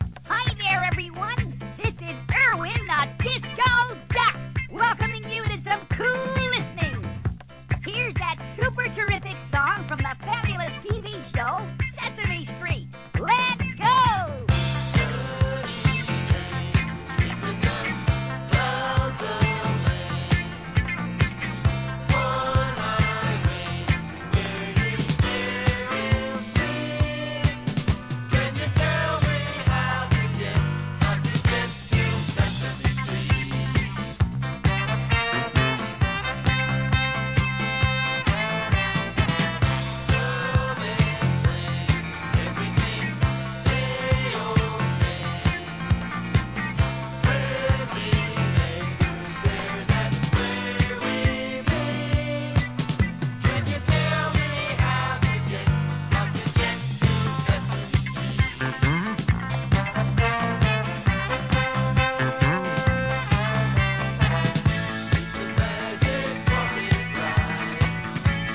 SOUL/FUNK/etc...
老若男女楽しめるDISCO物!!